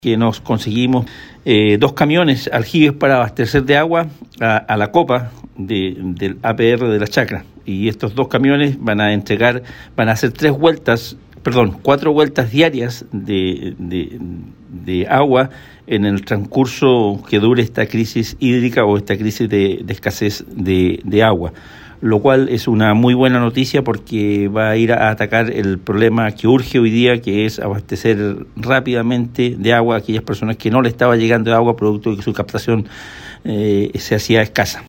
Luego hubo una reunión en la gobernación provincial, donde se informó de las soluciones tanto a largo plazo como en lo más inmediato, que es la entrega de agua en camiones aljibe, como lo informó el día martes el gobernador provincial, Pedro Andrade.